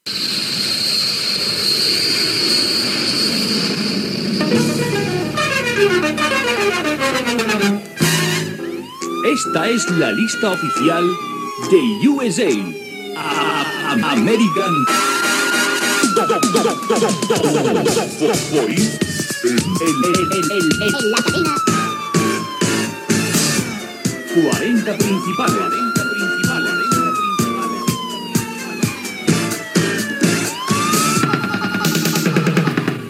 Indicatiu del presentador i del programa.